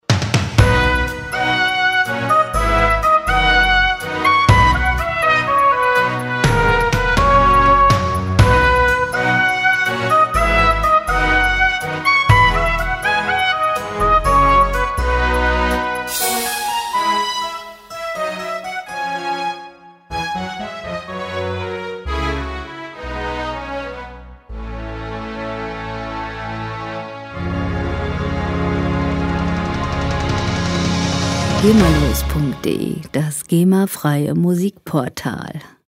lizenzfreie Werbemusik für Imagefilme
Musikstil: Pop Classic
Tempo: 123 bpm
Tonart: A-Dur
Charakter: beschwingt, heiter